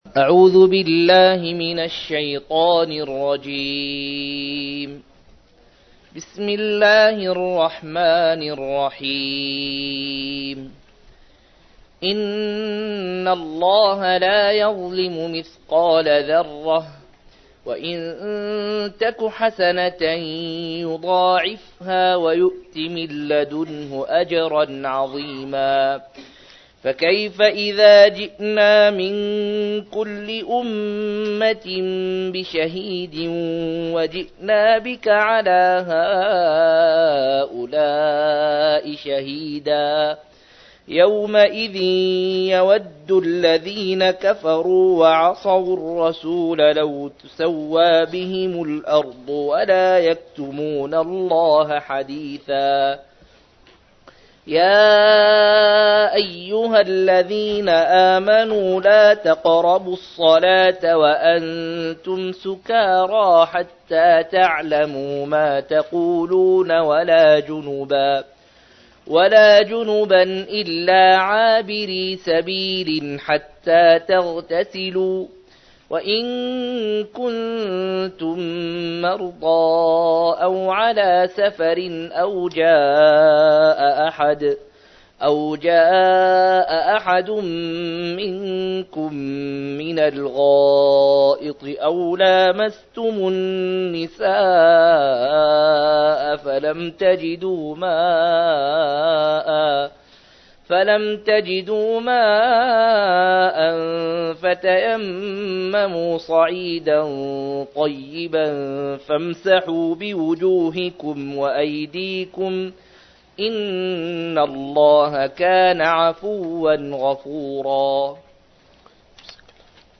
089- عمدة التفسير عن الحافظ ابن كثير رحمه الله للعلامة أحمد شاكر رحمه الله – قراءة وتعليق –